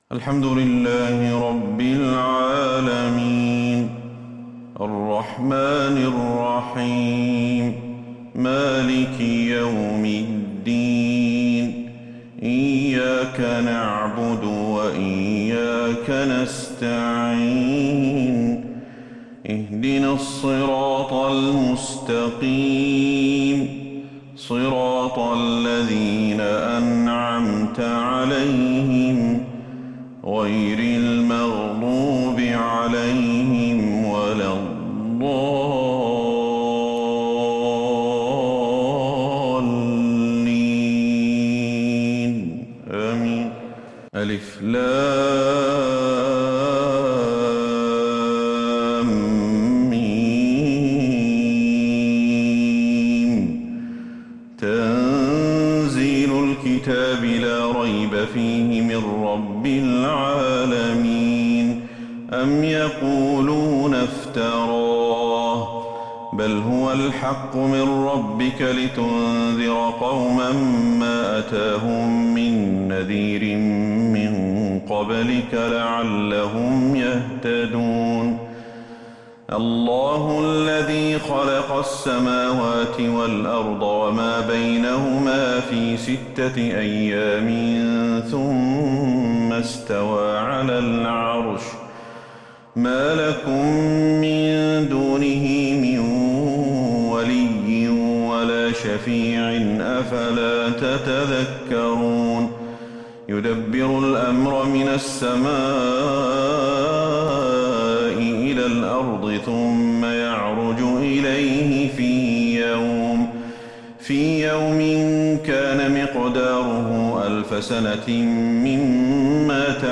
صلاة الفجر للقارئ أحمد الحذيفي 16 شوال 1442 هـ
تِلَاوَات الْحَرَمَيْن .